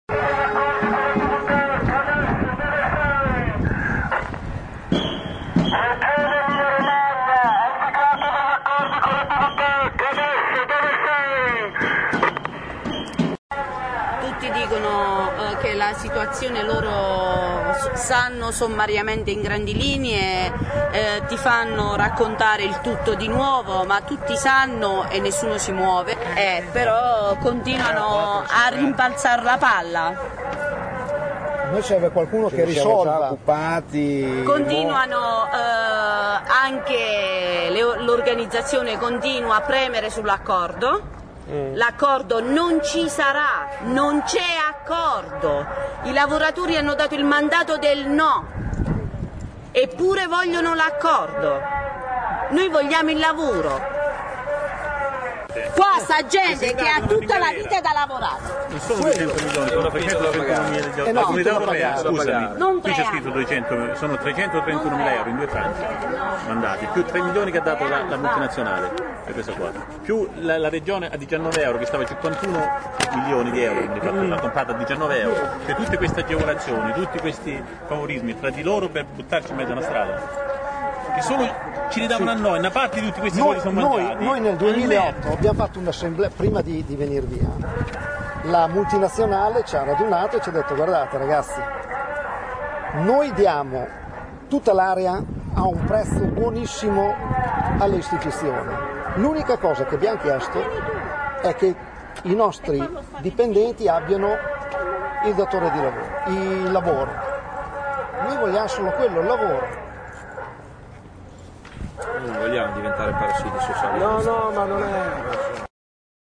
Striscioni, bandiere della Fiom, un paio di vuvuzele e un megafono che in loop urlava “Regione Emilia Romagna, hai siglato degli accordi con la Bv tech, e adesso dove sei?” hanno colorato la protesta dei lavoratori che speravano di incontrare l’assessore alle attività produttive, Giancarlo Muzzarelli, prima dell’incontro ufficiale, fissato per lunedì prossimo.
Ascolta i lavoratori